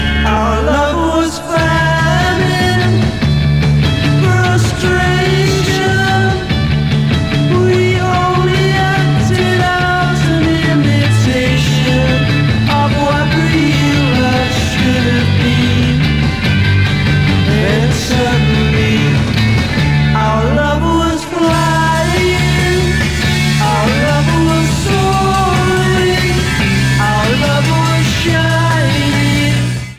BBC recordings.
Sound Samples/Track Listing (All Tracks In Mono)